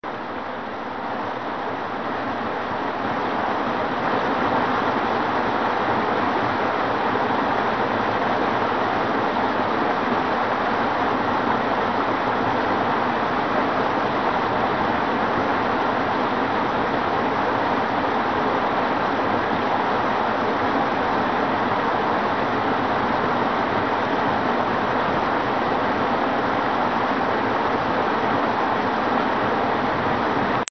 Sri Lanka » Railway station announcement2 sinhala kollupitiyaSri Lanka
描述：Kaluthara train Railway Announcement by sinhala at Fort station Sri Lanka
标签： lanka railway station rail sri train announcement
声道立体声